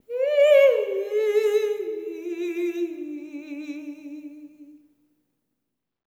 ETHEREAL02-R.wav